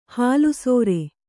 ♪ hālu sōre